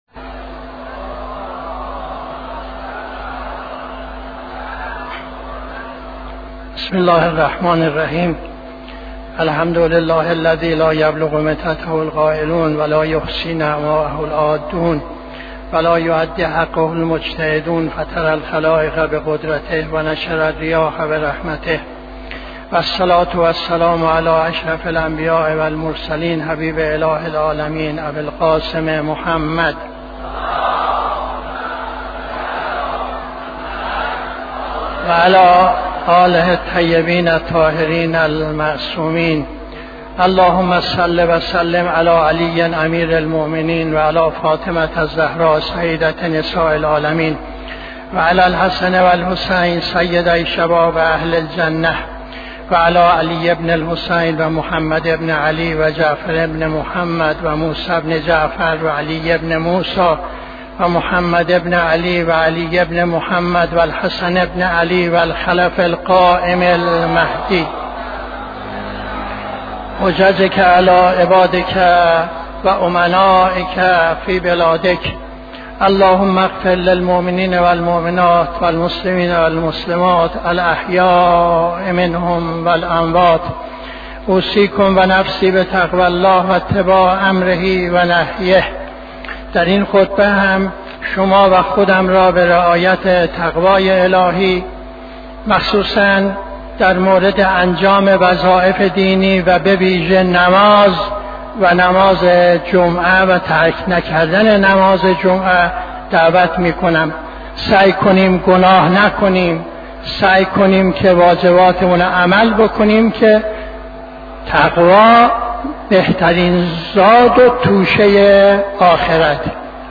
خطبه دوم نماز جمعه 23-12-81